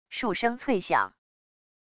数声脆响wav音频